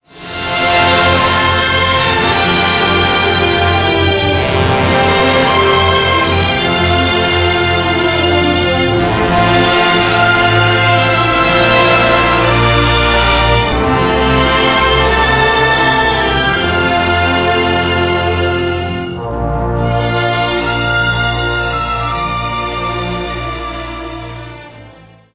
영화 예고편에서